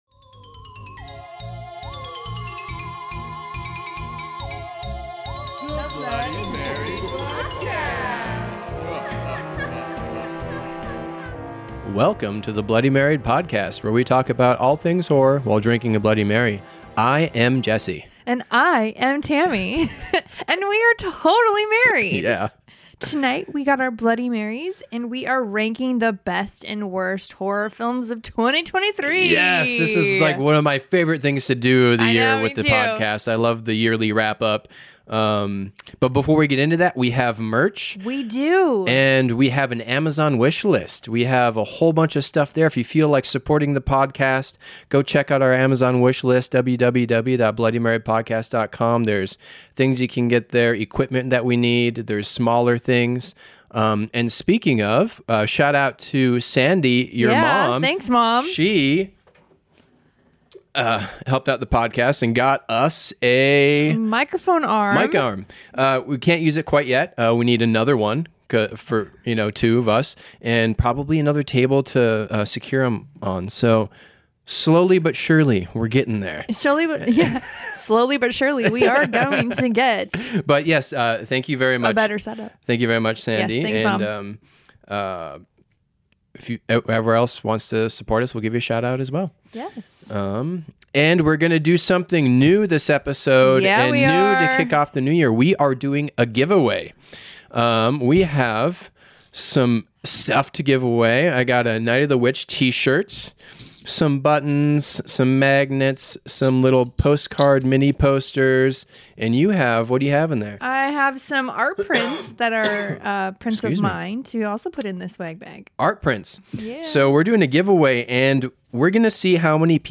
a totally rad couple, as they have a heart-to-heart about all things horror while drinking Bloody Marys.